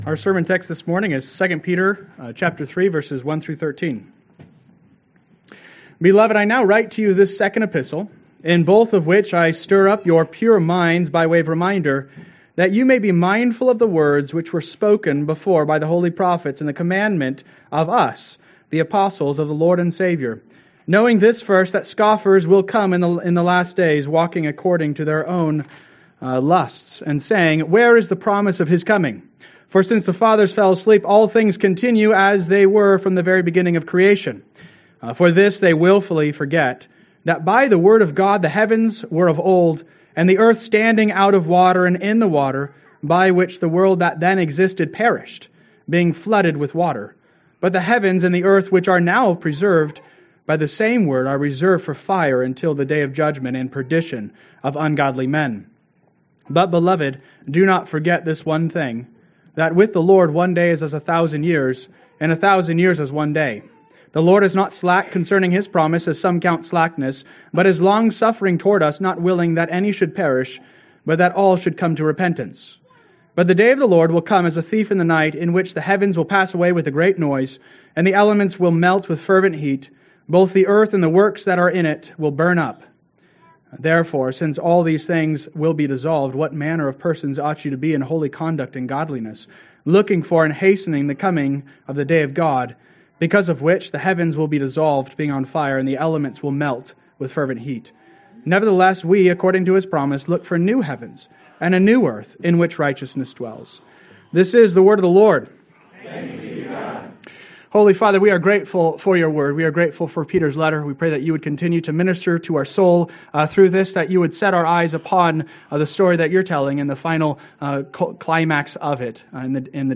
Sermon Outline: 11-17-24 Outline 2 Peter 3a (The Day of the Lord)